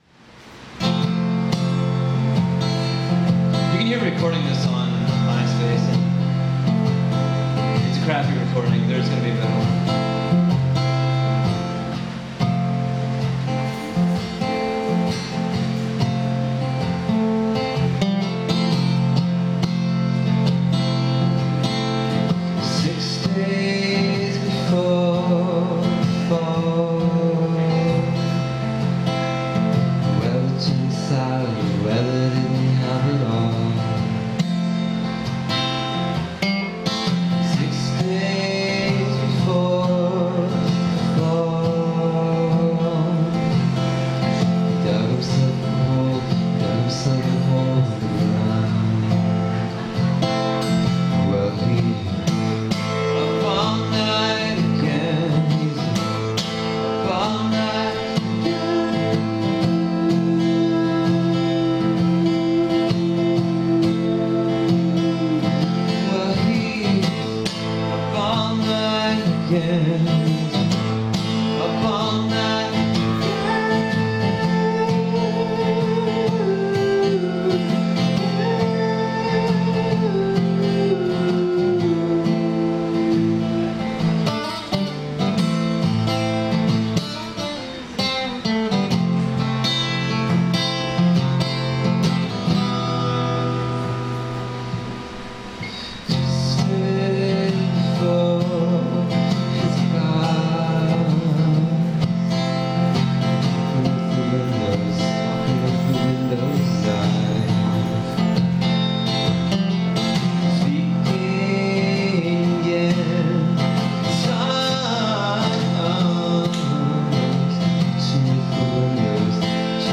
Twilight of the Gods (Live at the Weirder Park General Store